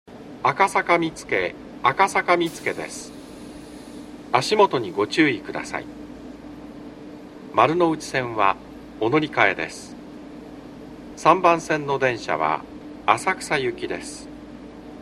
B線ホームで収録すると、真上にA線の線路があるのでよくガタンゴトンと被ります・・。
接近放送